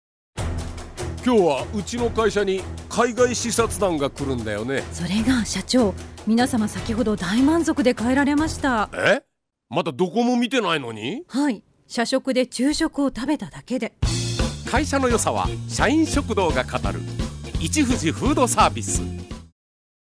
ラジオCM作品 受賞者決定！！